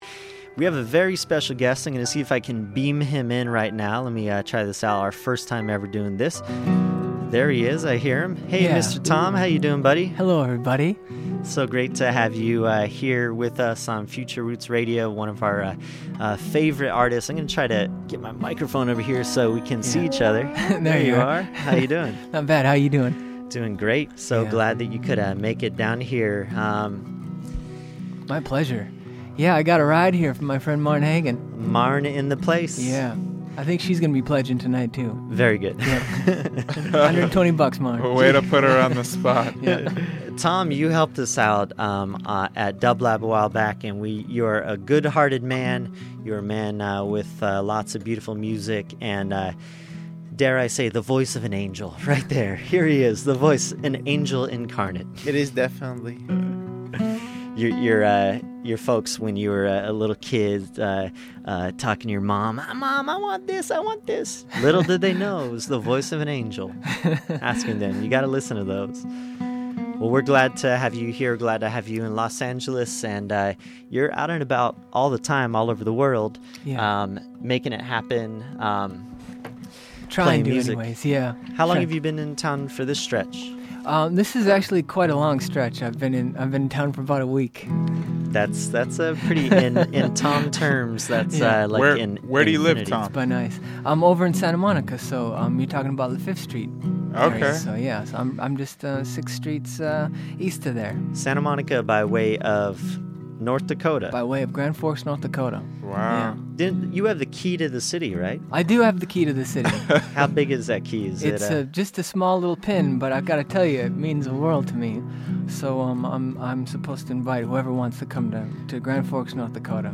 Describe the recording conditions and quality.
(LIVE SET)